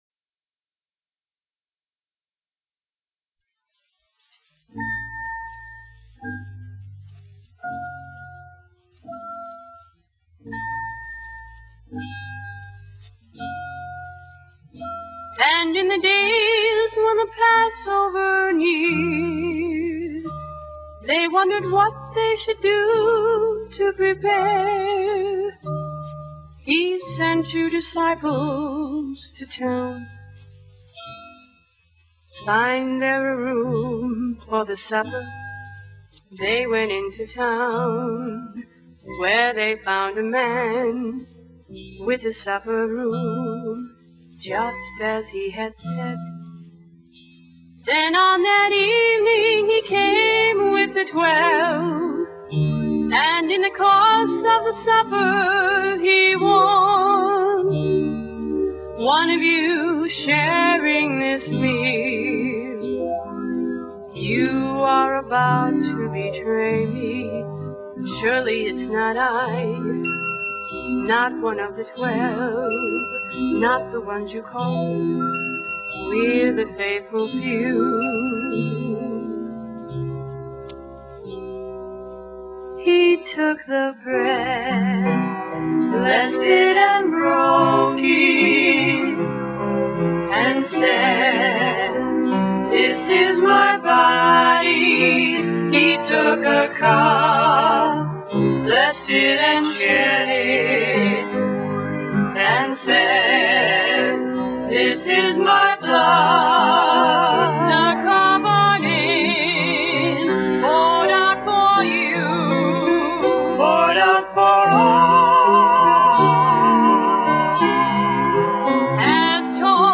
VOCALISTS
ORGAN, SYNTHESIZER
GUITAR, SYNTHESIZER
BASS GUITAR
DRUMS